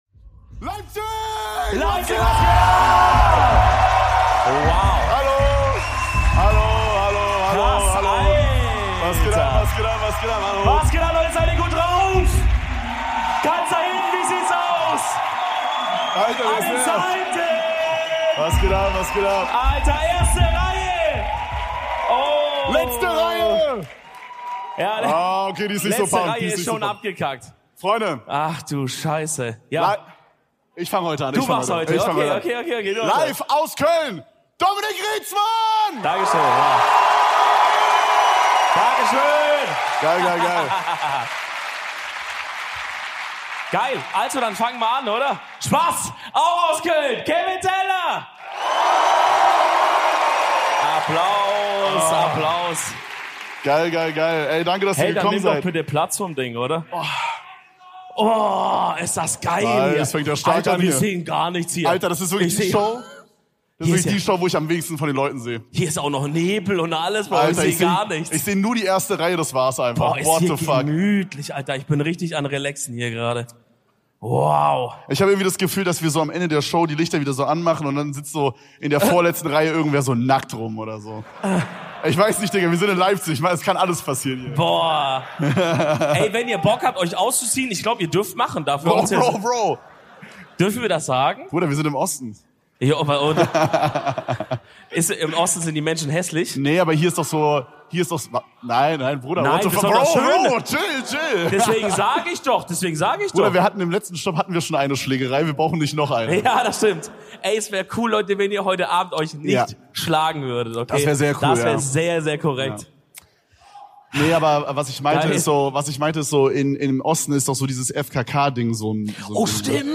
In der heutigen Folge durften wir im Weinkeller in Leipzig vor euch rechtfertigen